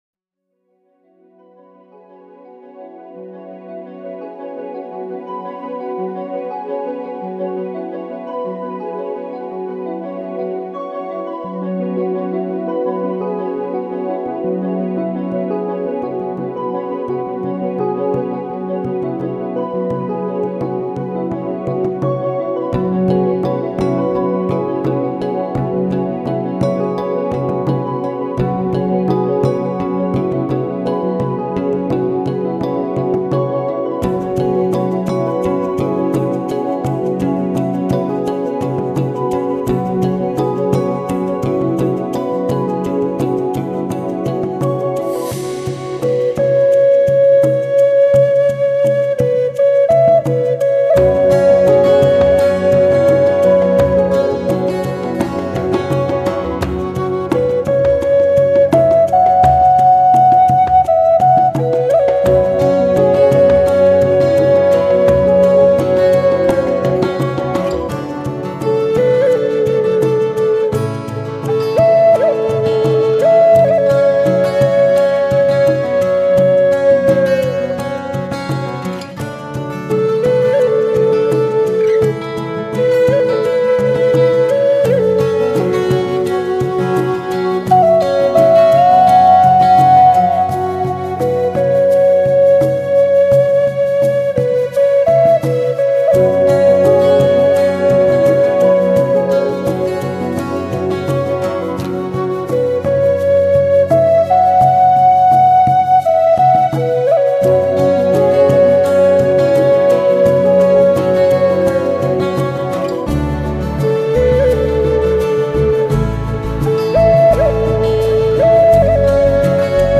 运用吉 他，键盘，打击乐，长笛，小提琴和中提琴创作出美妙的音符，象细雨 一样轻柔的在听者耳边飘过 ... 05